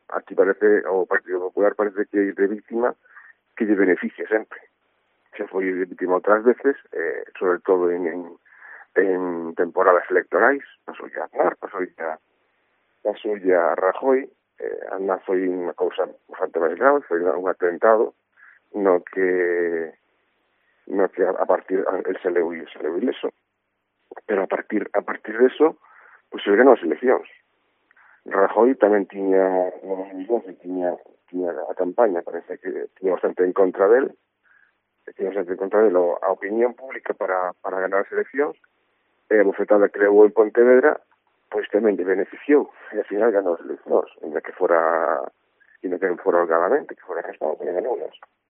Declaraciones a COPE de Javier Dios, concejal del PSOE de Vilanova, tras los ataques sufridos por el alcalde